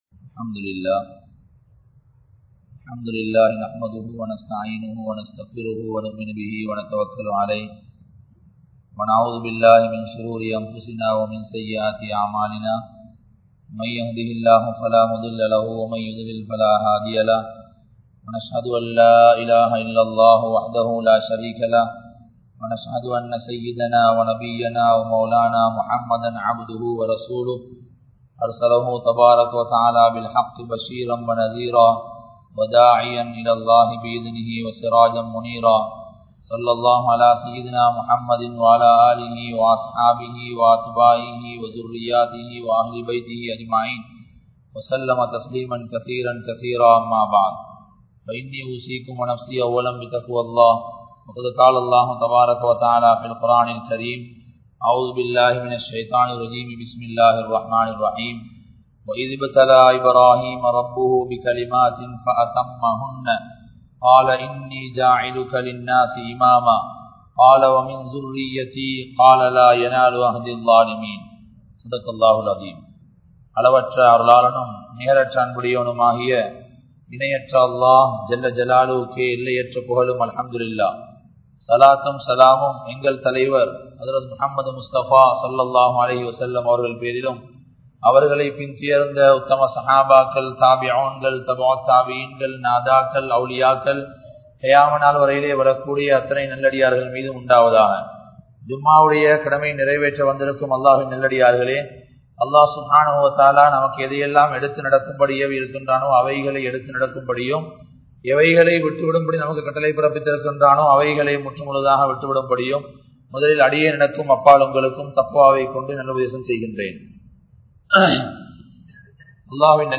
Allah Poathumaanavan(அல்லாஹ் போதுமானவன்) | Audio Bayans | All Ceylon Muslim Youth Community | Addalaichenai
Colombo 12, Aluthkade, Muhiyadeen Jumua Masjidh